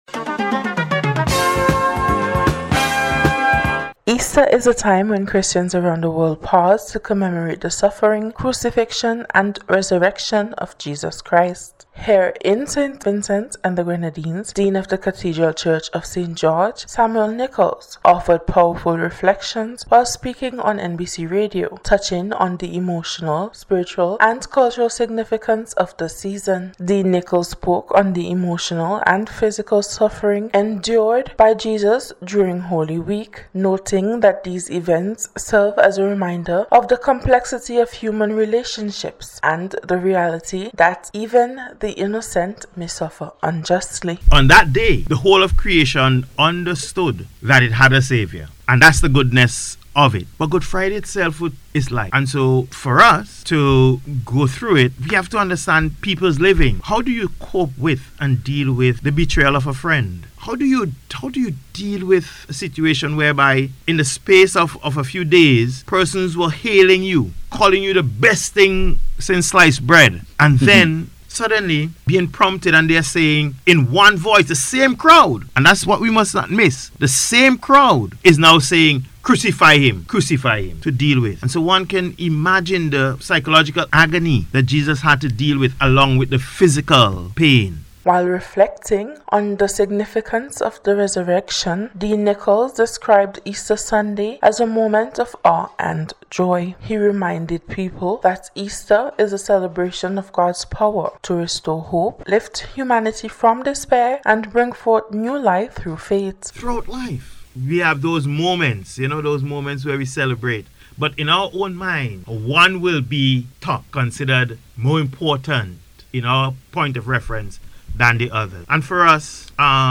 EASTER-RELIGION-REPORT.mp3